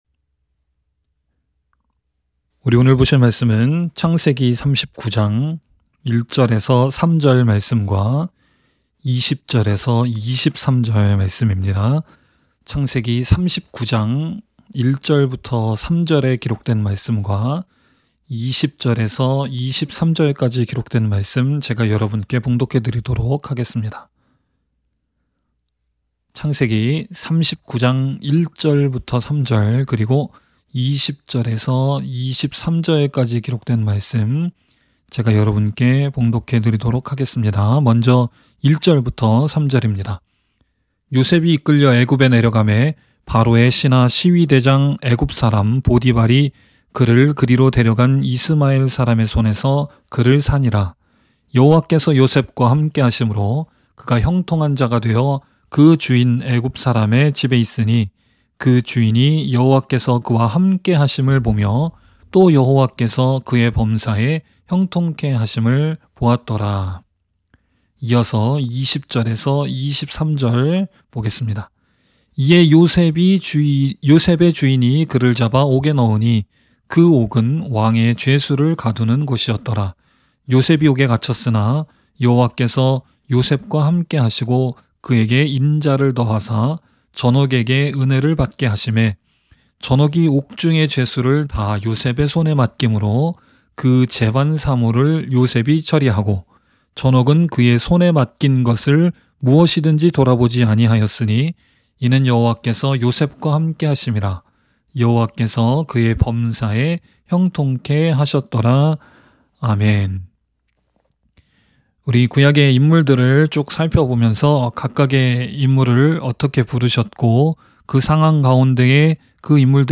by admin-new | Feb 28, 2022 | 설교 | 0 comments